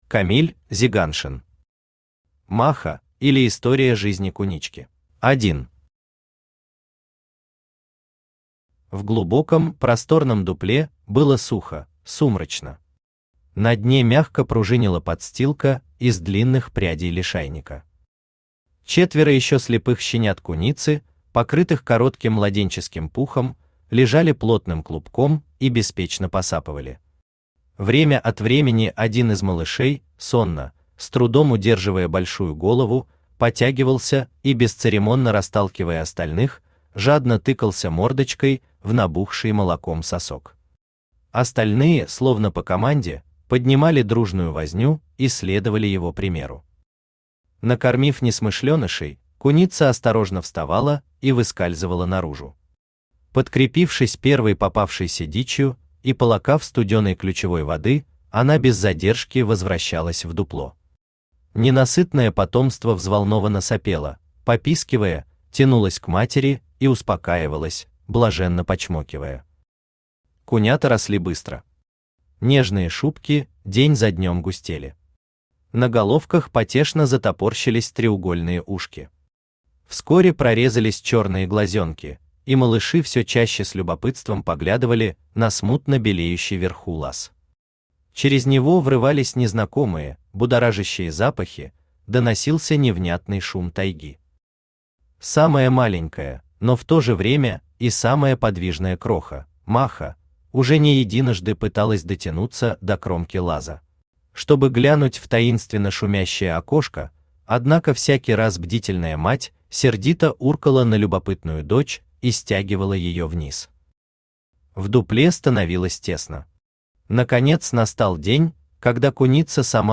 Аудиокнига Маха, или История жизни кунички | Библиотека аудиокниг
Aудиокнига Маха, или История жизни кунички Автор Камиль Фарухшинович Зиганшин Читает аудиокнигу Авточтец ЛитРес.